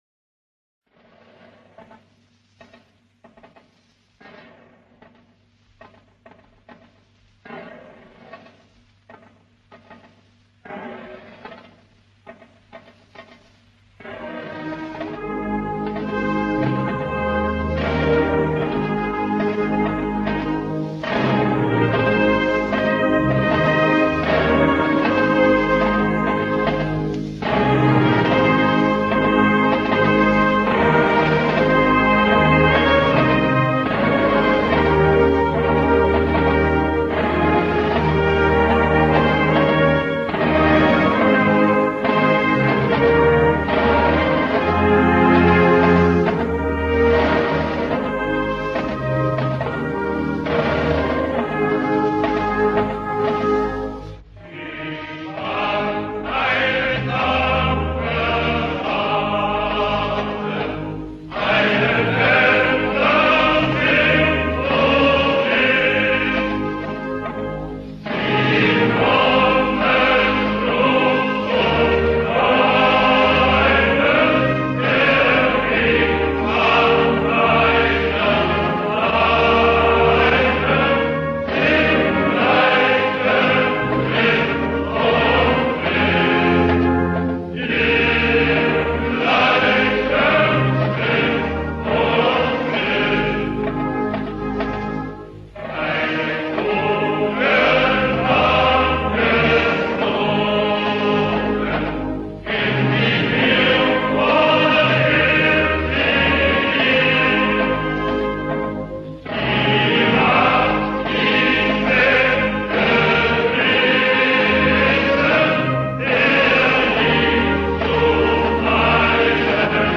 Text und Chorfassung